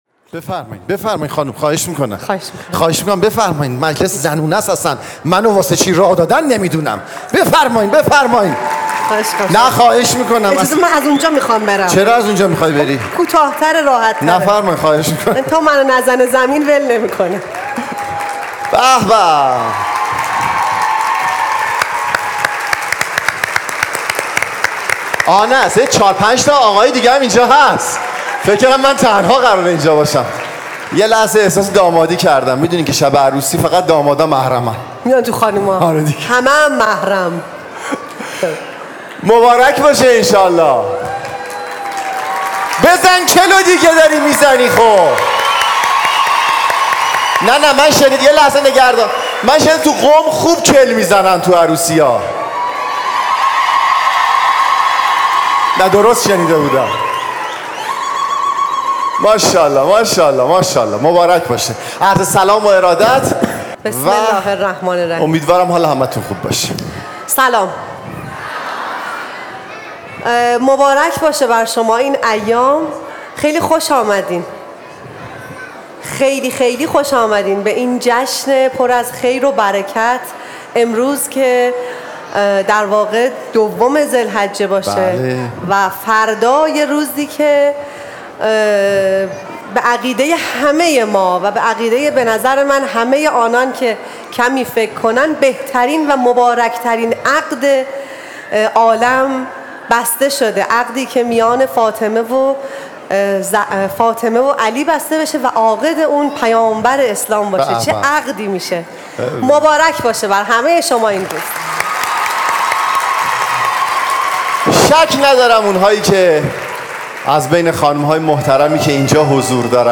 نمایش طنز
سالروز ازدواج حضرت زهرا